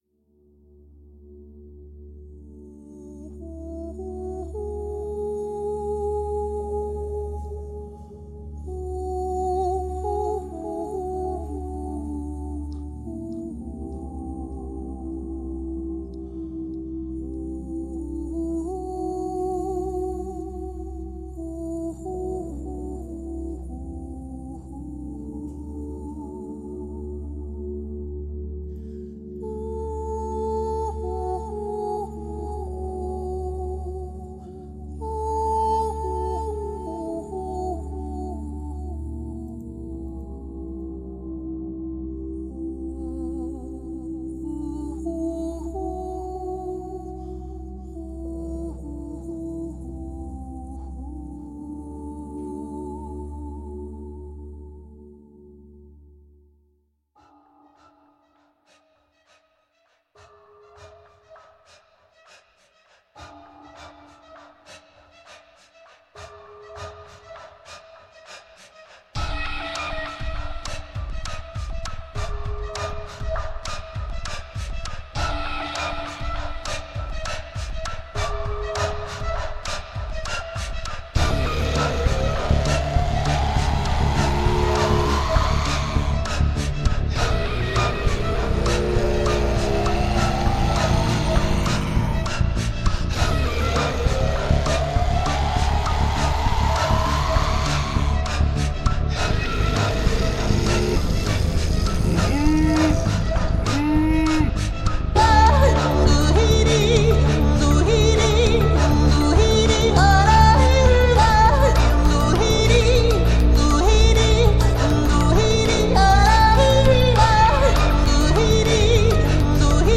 Comme le sculpteur, la musicienne soude ses matières en superposition : les ambiances bruitistes évoquent la peau de l’animal, ses écailles, ses piquants, sa rugosité. Puis la rythmique vient suggérer son mouvement et les torsions de sa musculature. Enfin la mélodie, découpée au chalumeau, traduit la voix de la bête, sa respiration intime et son message.